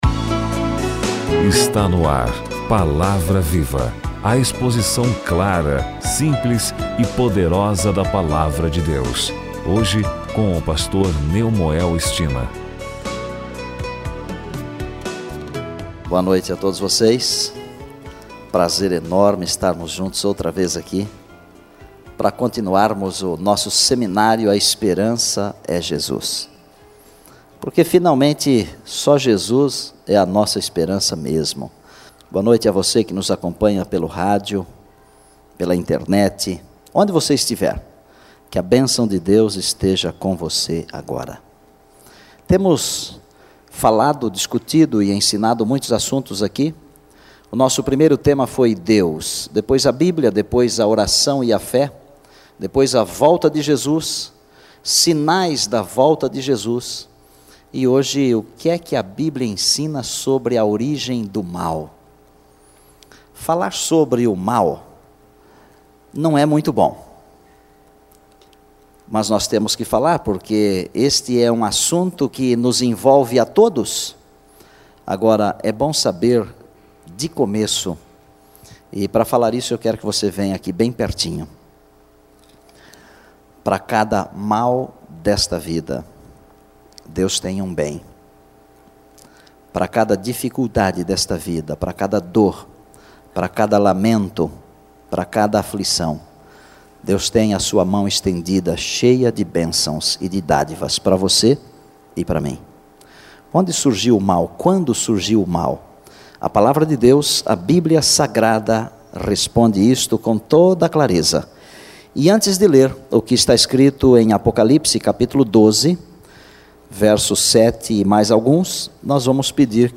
Sermão